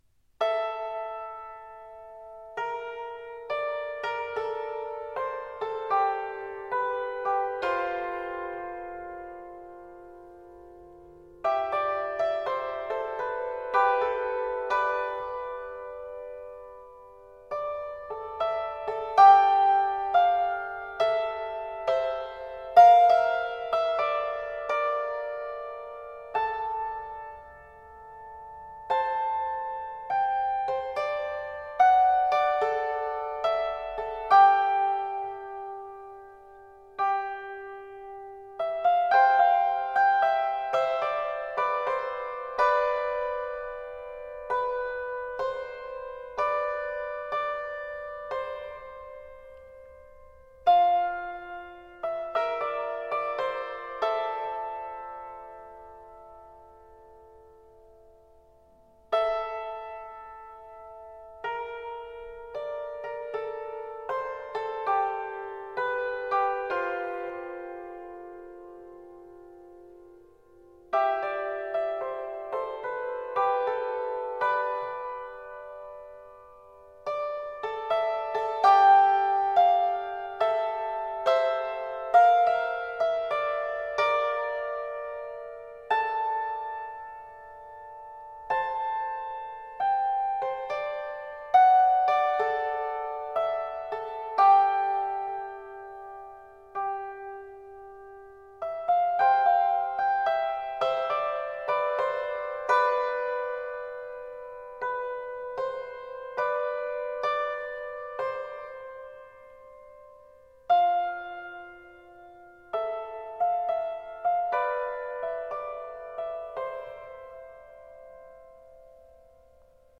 Vocal [89%] Choral [11%]